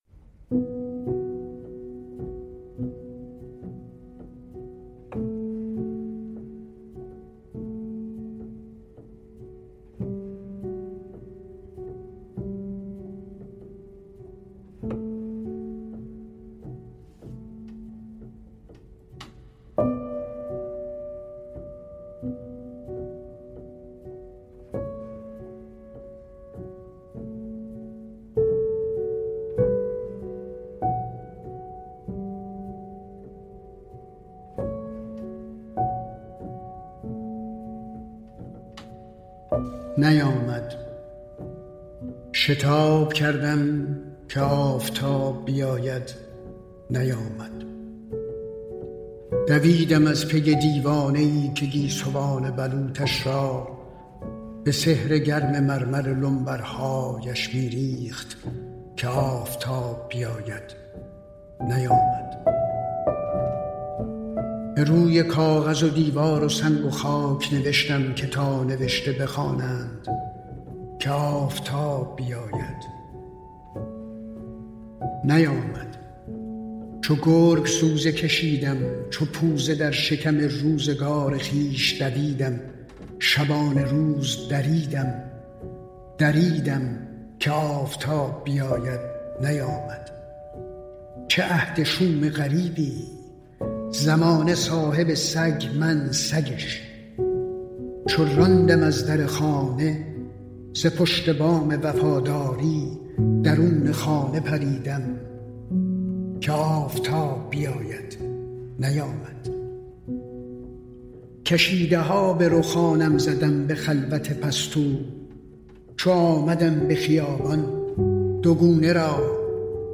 شعرخوانی‌های رضا براهنی